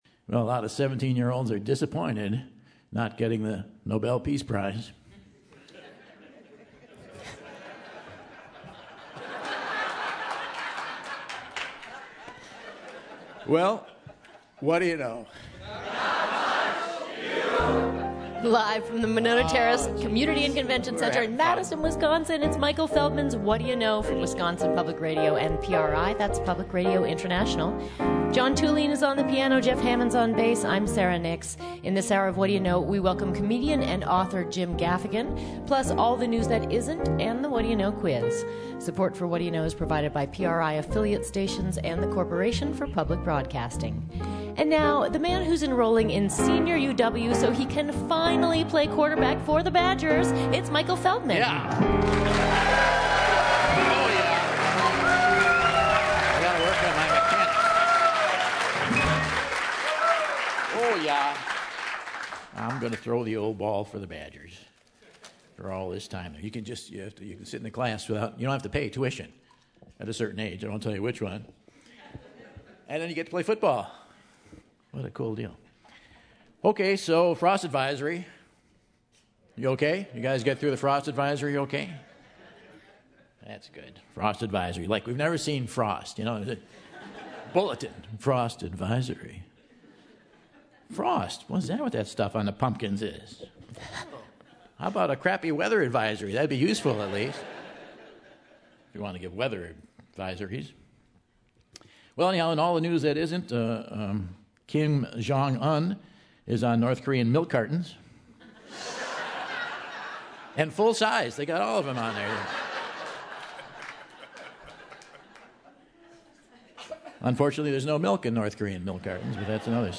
October 11, 2014 - Madison, WI - Monona Terrace | Whad'ya Know?